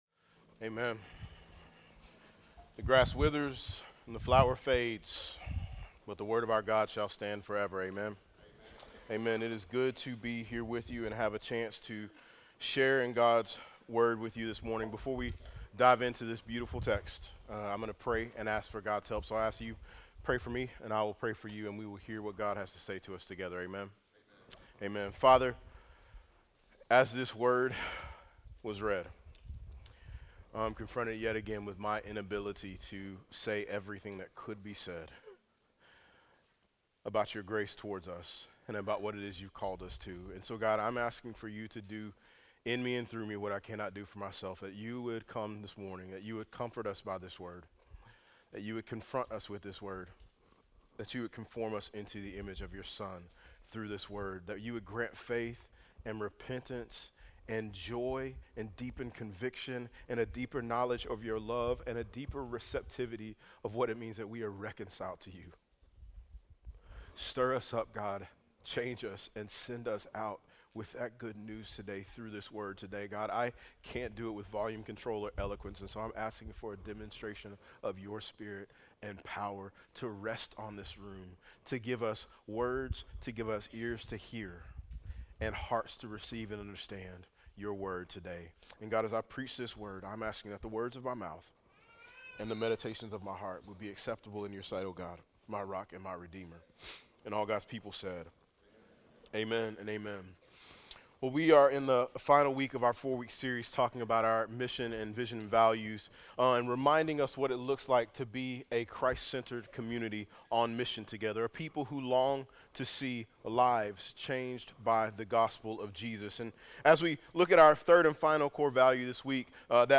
Genesis 16 View this week’s sermon outline